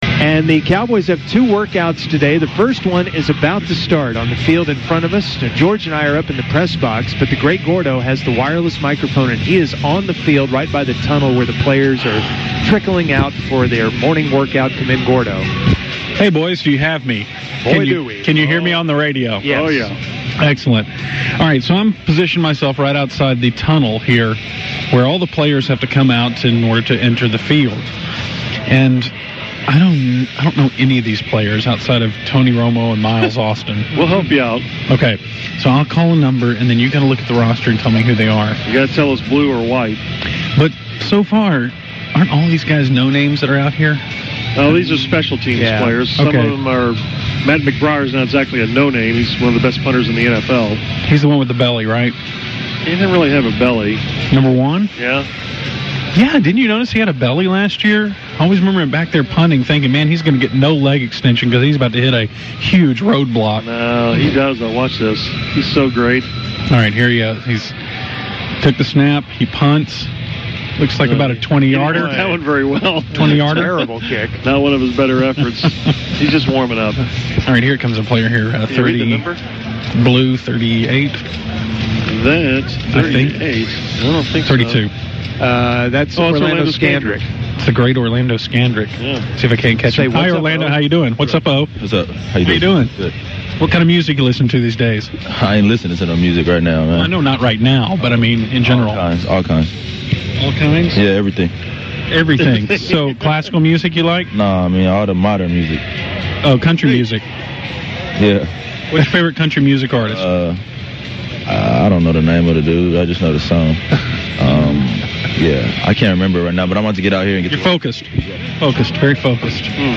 The Ticket is at the Cowboys training camp which brings the fun of wireless segments.